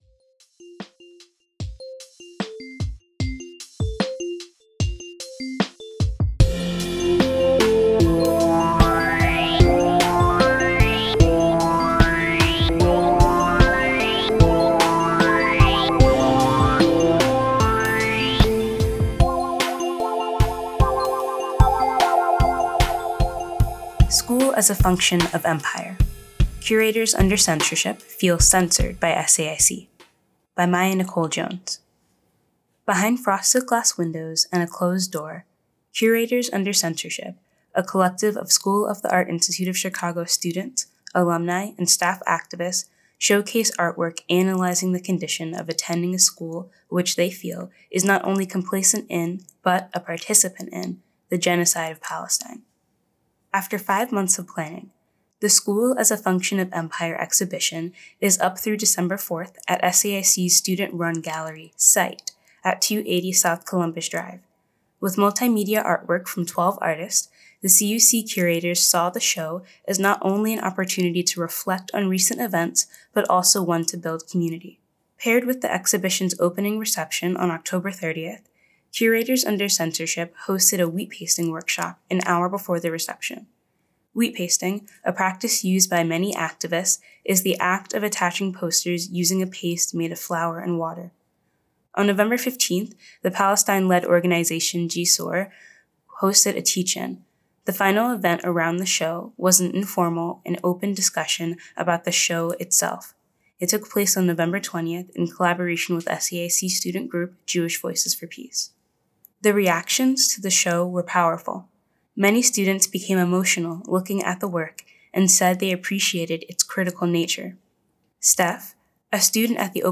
The text of this article was read aloud and recorded for your greater accessibility and viewing pleasure: